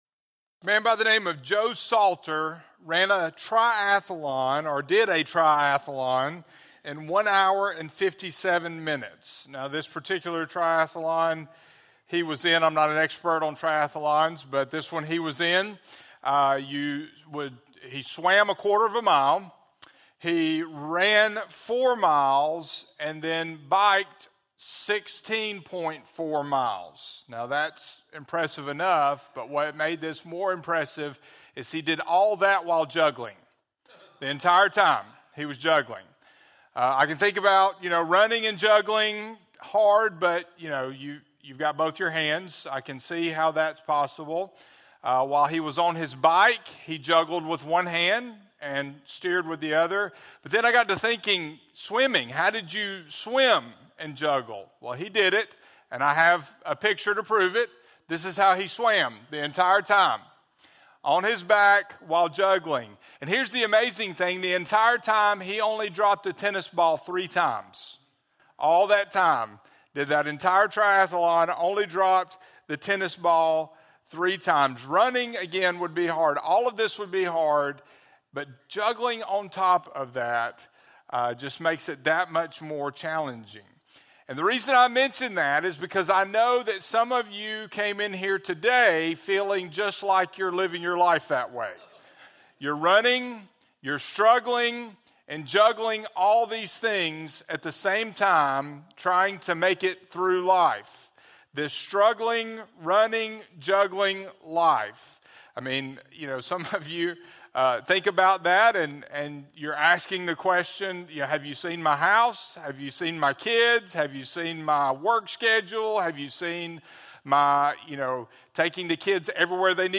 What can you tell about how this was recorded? The Shepherd’s Peace – Wall Highway Baptist Church